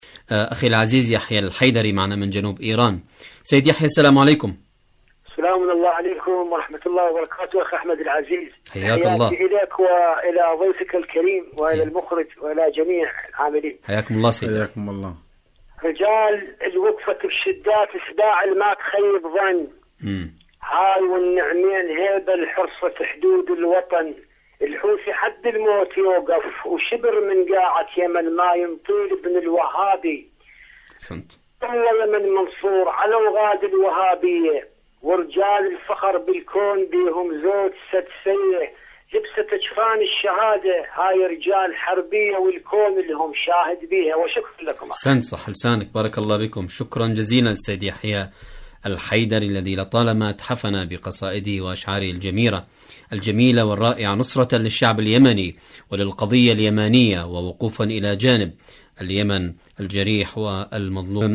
برنامج : اليمن التصدي والتحدي /مشاركة هاتفية